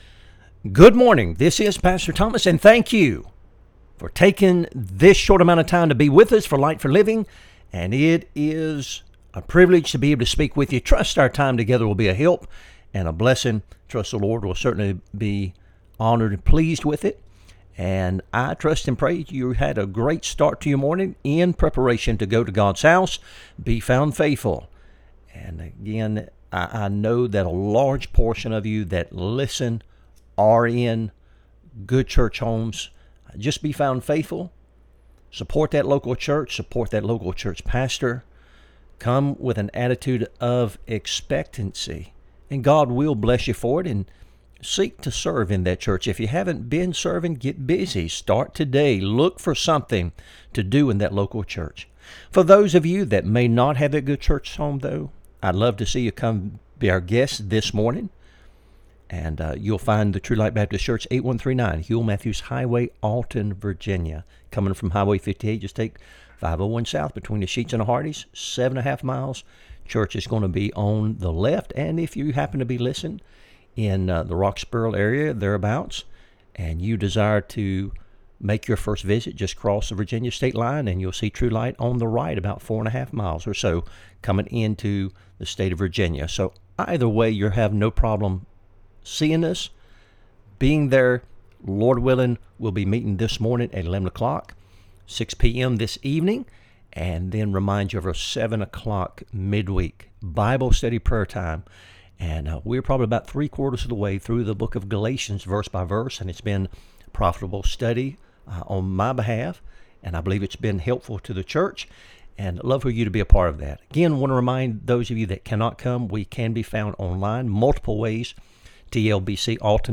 Light for Living Radio Broadcast